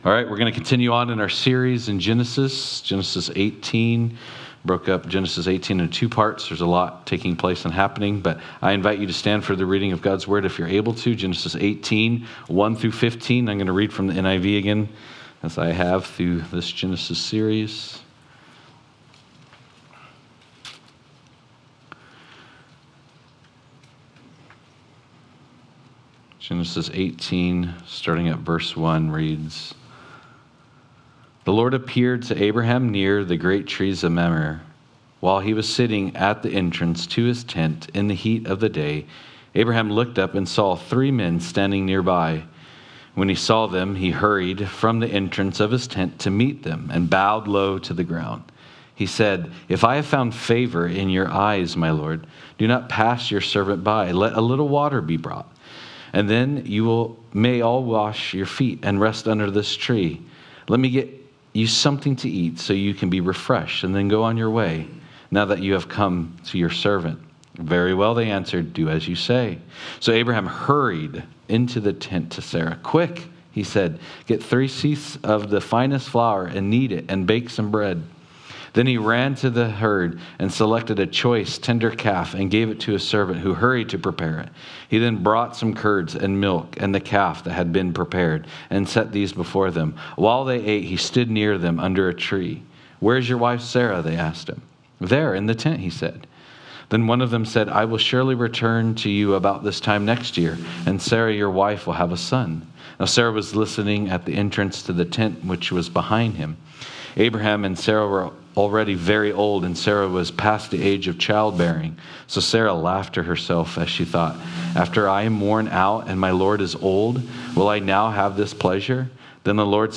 Genesis Service Type: Sunday Morning « Genesis-In the Beginning